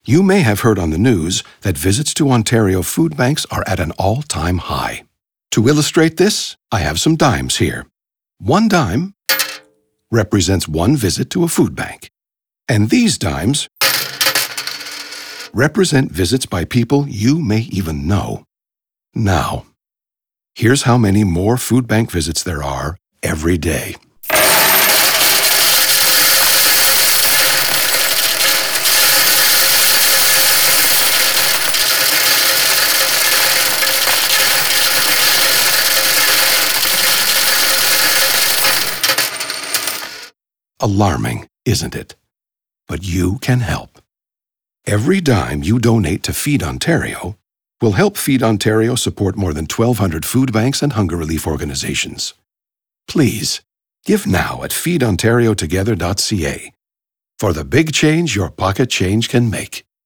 We believe it takes 60 seconds to properly demonstrate aurally the mind-boggling number of ordinary Ontarians who require food banks.